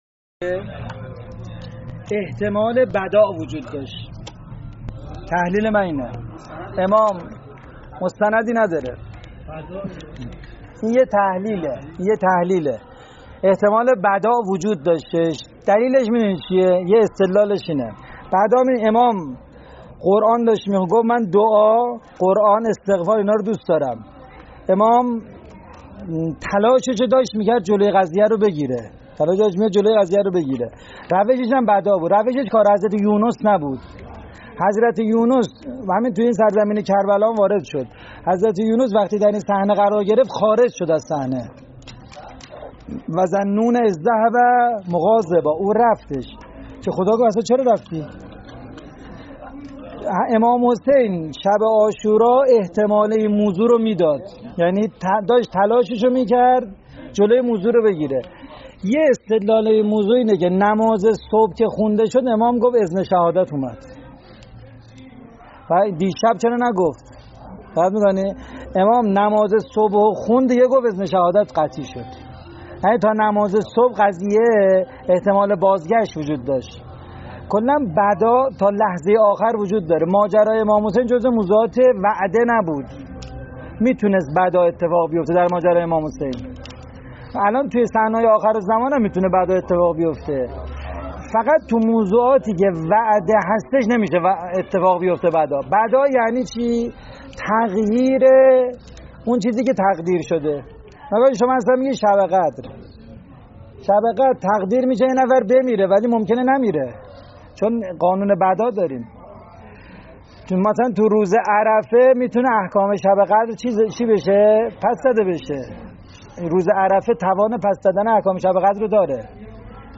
فایل صوتی سخنرانی